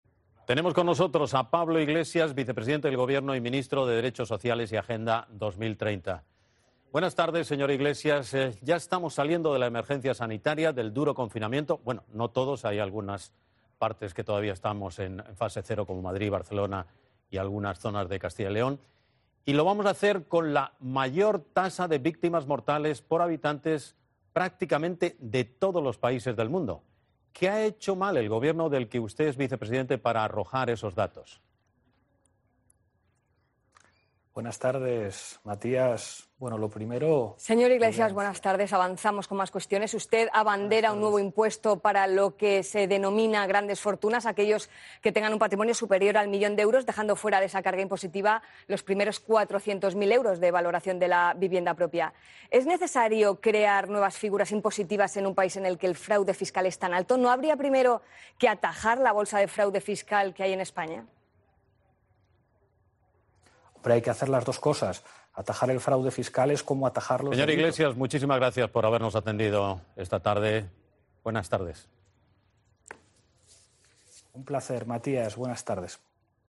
Después de no haber saludado a Carrillo ni haberse referido a ella por su nombre en ningún momento, Pablo Iglesias se despide de la entrevista con un “buenas tardes Matías”. Un detalle que no puede apreciarse en el vídeo completo que ha compartido la televisión a través de su portal web pero que en COPE hemos recuperado y editado para que pueda apreciarse tres momentos claves: el arranque de la entrevista, el saludo de Mónica Carrillo y el final de la intervención del vicepresidente en Antena 3.